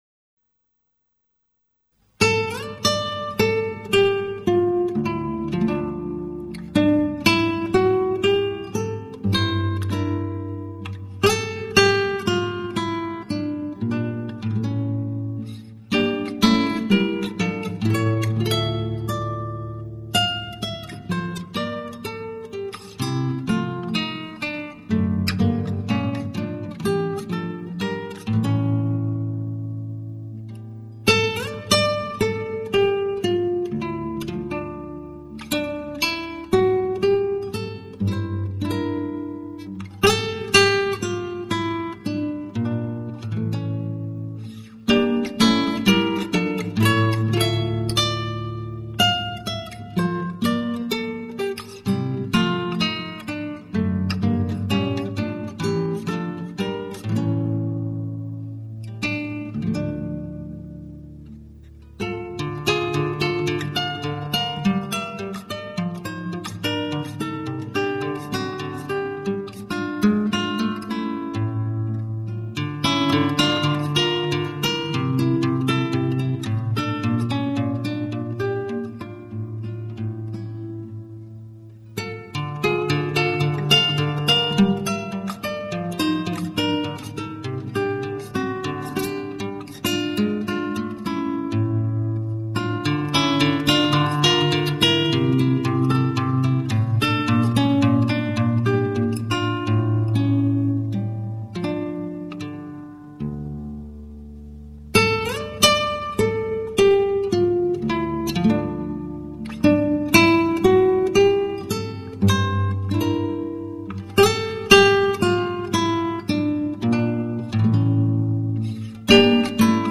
0149-吉他名曲恩特查.mp3